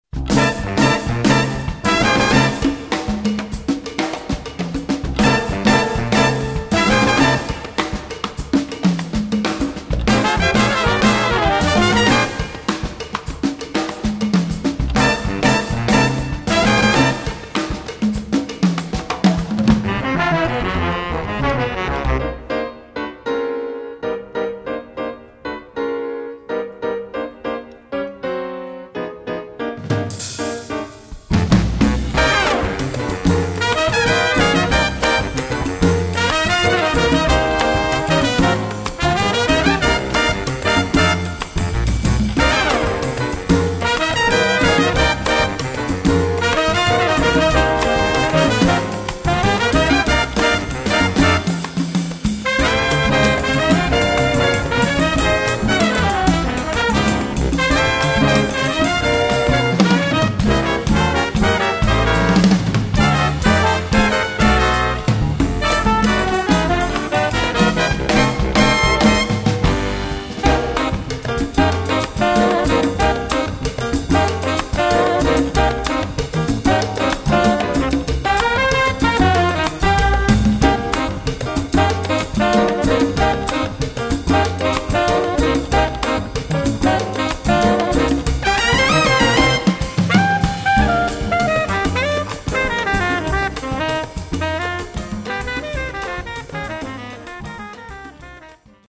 Category: combo (nonet)
Style: mambo
Solos: open